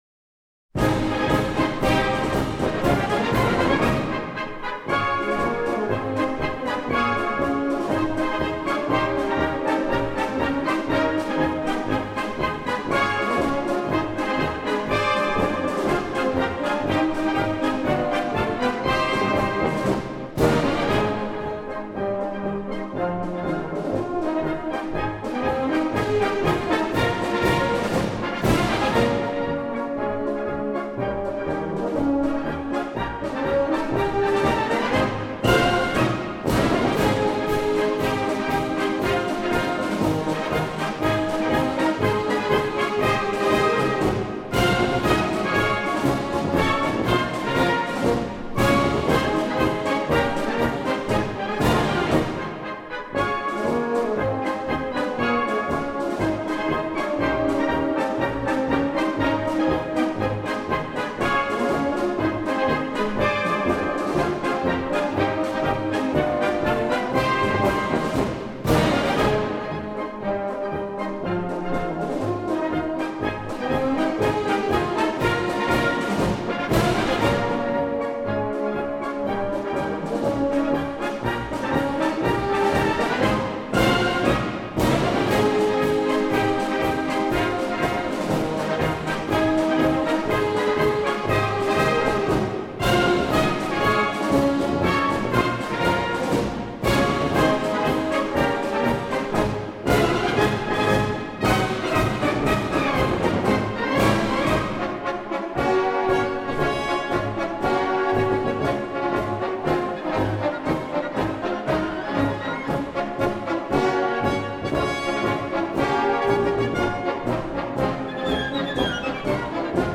Описание: Бодрый марш на мотив известной спортивной песни.